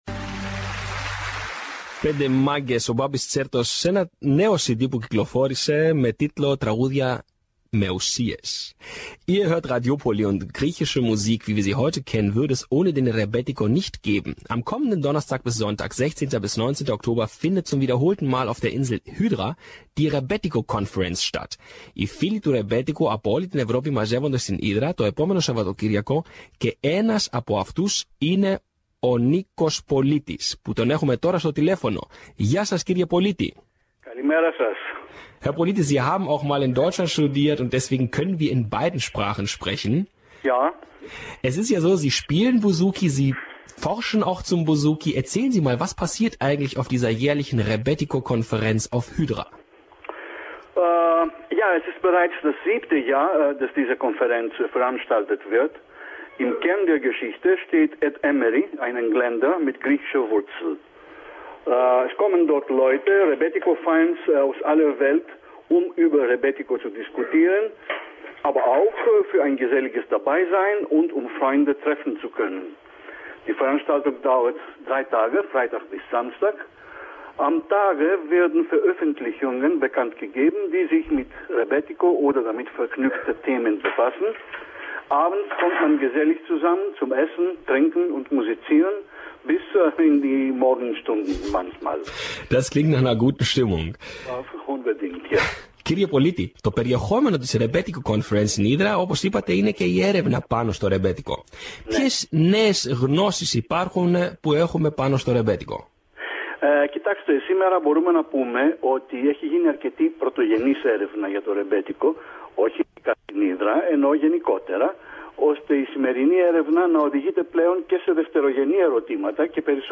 Ψάχνοντας στο διαδίκτυο βρήκα κάποια παλαιότερη ηχογράφηση, όπου κάποιος ελληνικής καταγωγής (και μάλλον Έλληνας πολίτης) δημοσιογράφος στη Γερμανία είχε κάνει μίαν συνέντευξη μαζί μου με θέμα το ρεμπέτικο, η οποία μεταδόθηκε σε εκπομπή της Γερμανικής ραδιοφωνίας που στόχευε στους Έλληνες που ήταν τότε εγκατεστημένοι στη Γερμανία.